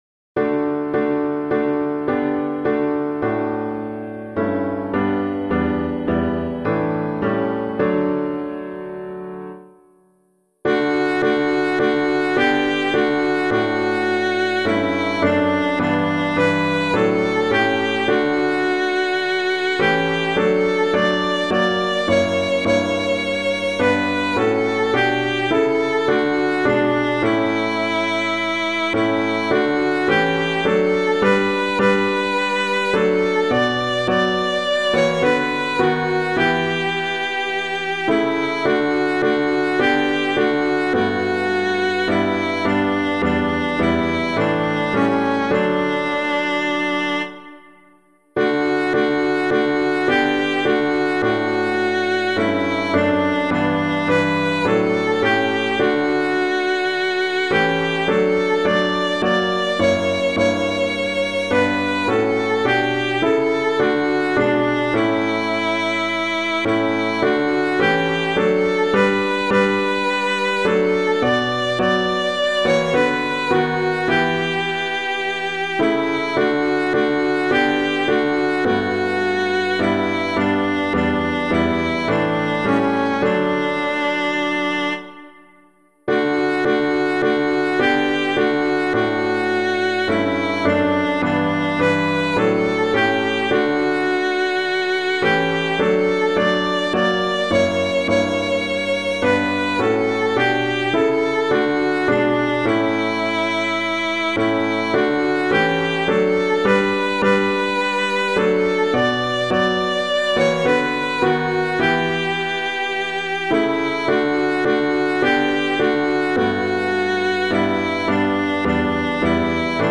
piano
If Christ Is Charged with Madness [Troeger - AURELIA] - piano.mp3